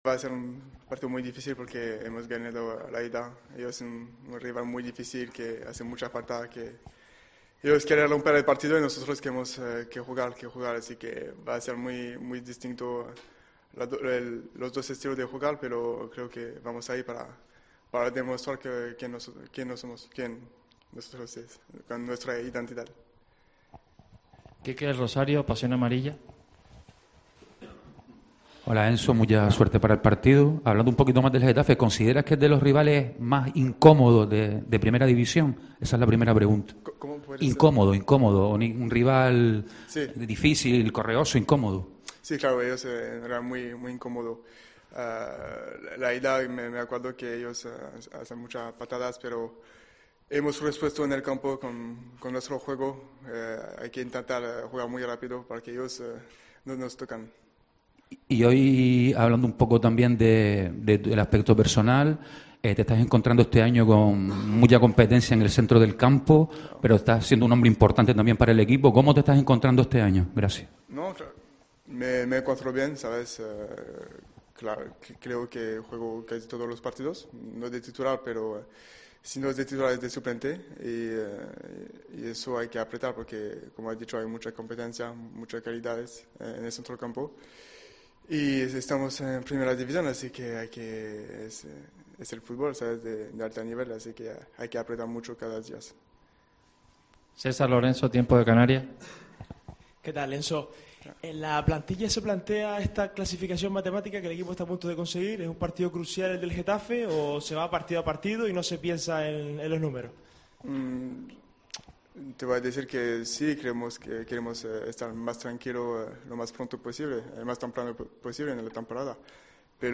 Enzo Loiodice compareció ante los medios en la previa del encuentro ante el Getafe CF. El centrocampista amarillo inició su intervención indicando que "creo que va a ser un encuentro muy difícil, será un partido con estilos distintos.